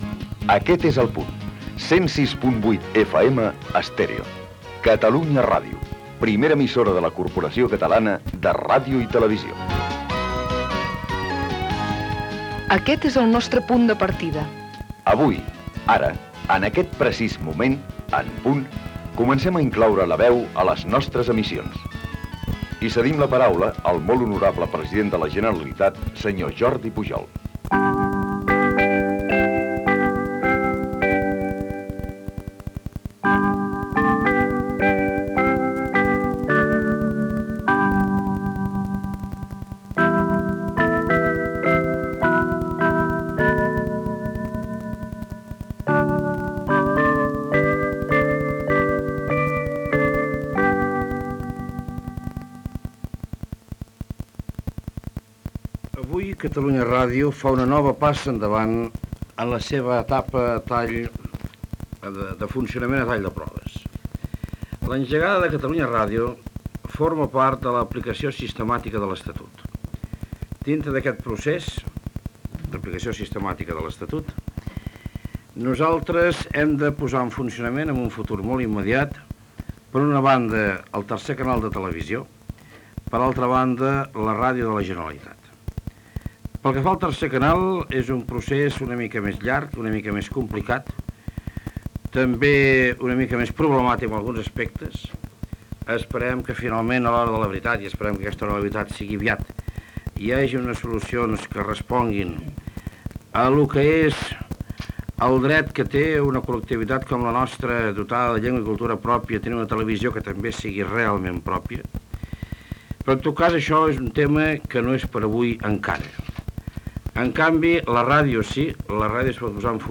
Paraules del president de la Generalitat Jordi Pujol.